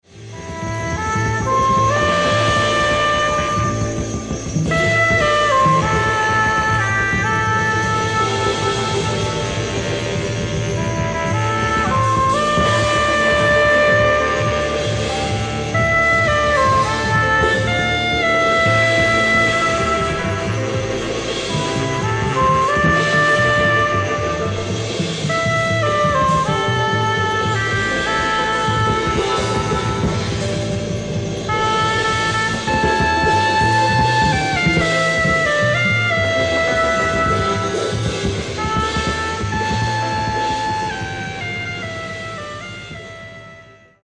フリー/レアグルーヴ/フレンチ・ジャズ